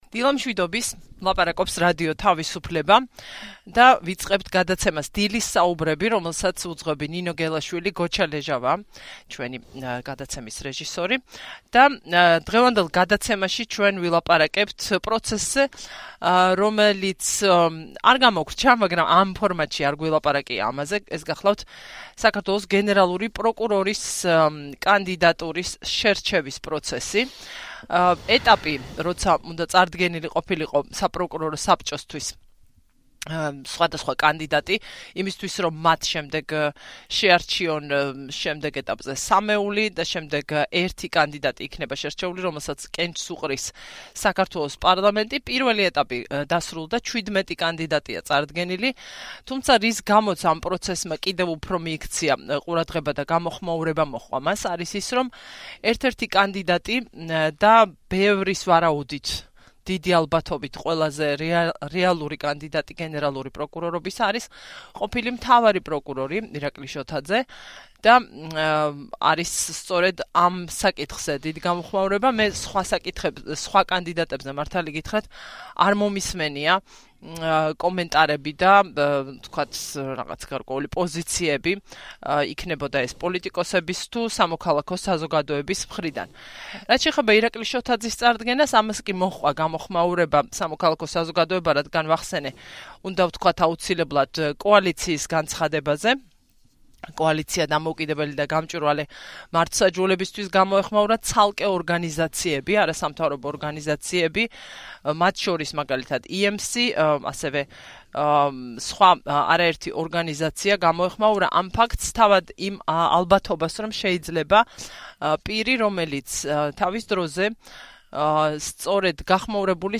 რომ ხელისუფლება პროკურატურაში ძველ კადრს დააბრუნებდა. ის რადიო თავისუფლების ეთერში ამბობს: